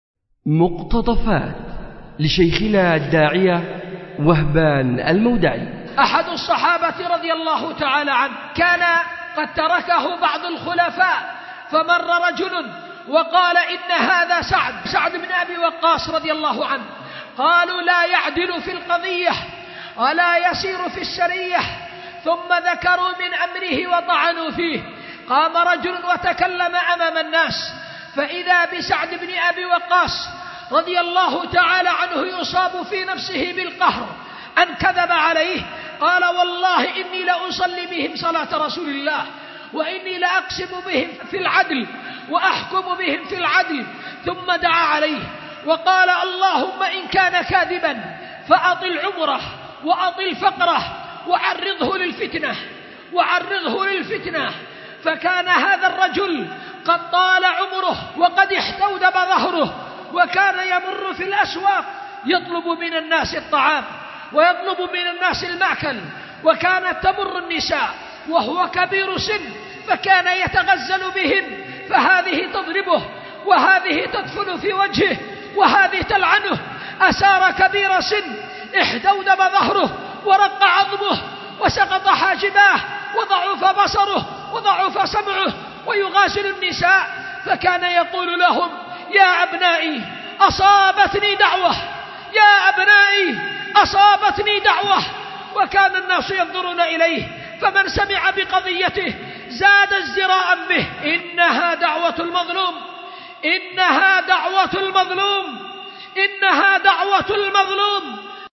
أُلقي بدار الحديث للعلوم الشرعية بمسجد ذي النورين ـ اليمن ـ ذمار ـ 1444هـ